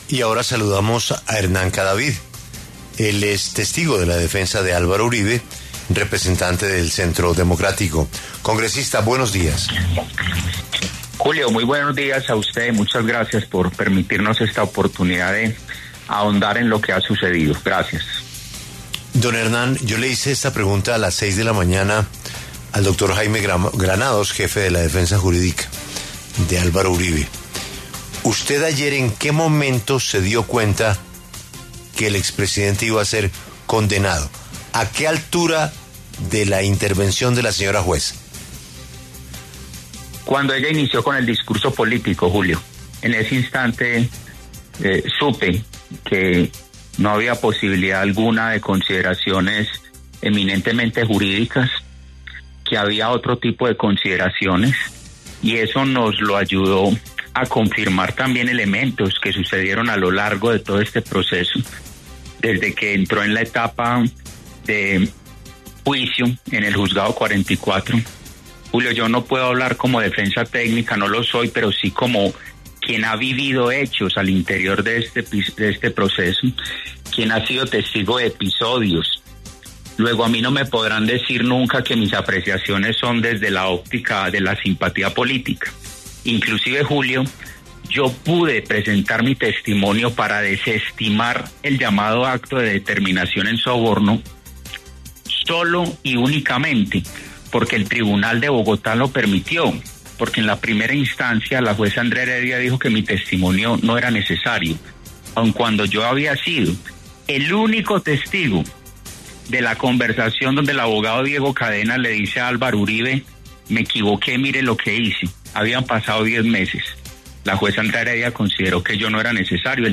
El representante Hernán Cadavid, del Centro Democrático, y testigo clave de la defensa del expresidente Álvaro Uribe, pasó por los micrófonos de La W, a propósito del fallo condenatorio que emitió la juez Sandra Heredia en contra del exmandatario por soborno en actuación penal y fraude procesal.